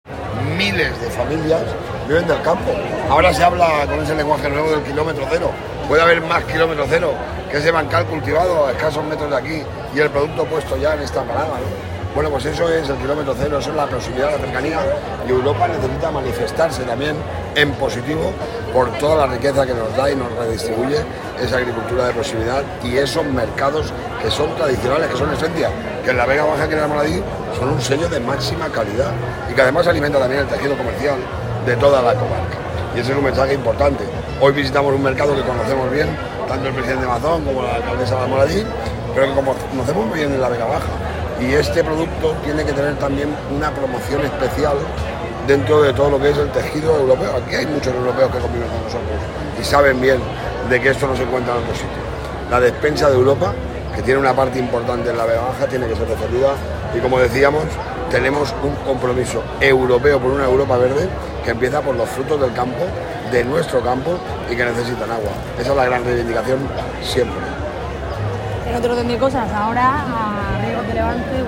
Visita Mercado Semanal Almoradí
Audio-Toni-Perez-Almoradi.m4a